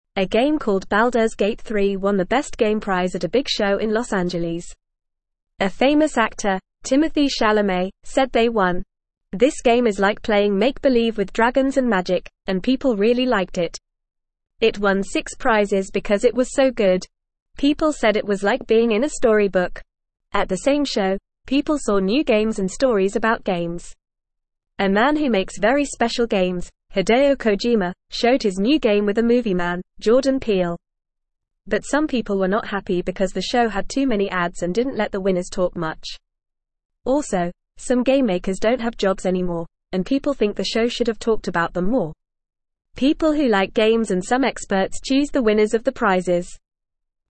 Fast
English-Newsroom-Beginner-FAST-Reading-Baldurs-Gate-3-Wins-Best-Game-Prize.mp3